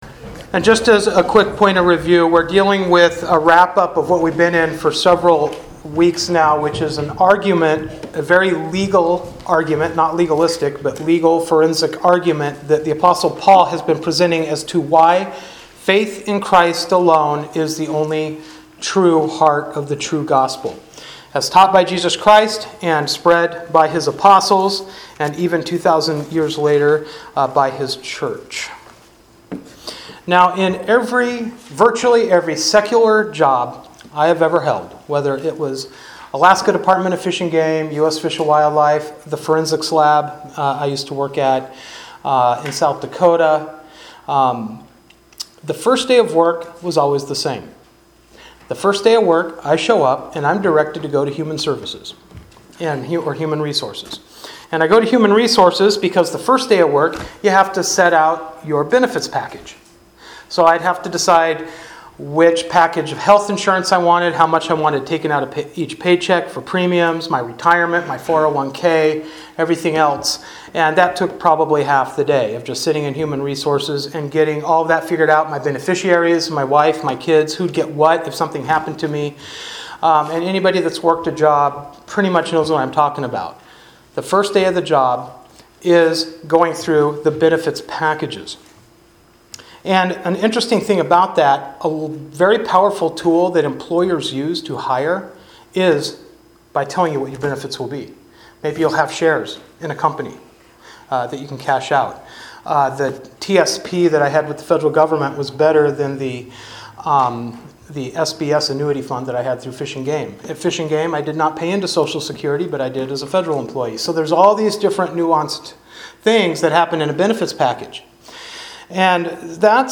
And this is actually how Paul ends the argument he began at the beginning of Galatians chapter 3: Listing the benefits we obtain from putting our faith in Christ alone. And we will be going through this list of benefits in this morning’s sermon.
Service Type: Sunday Morning Worship